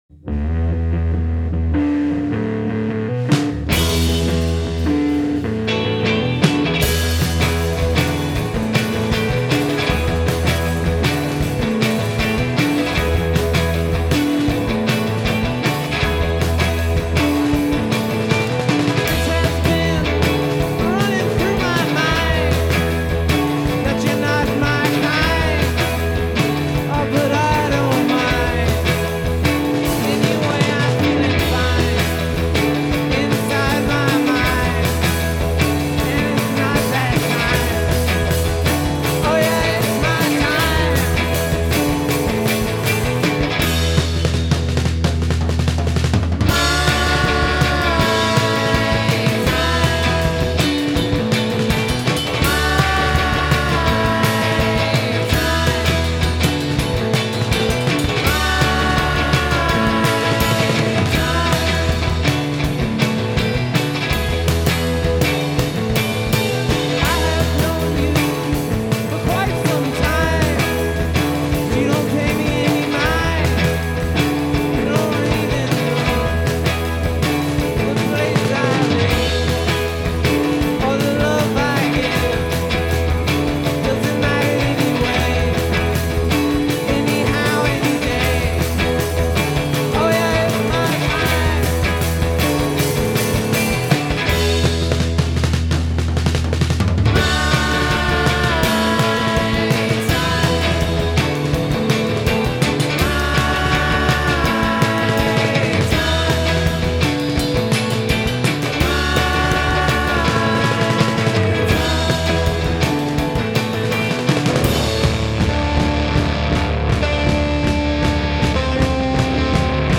Nashville Psych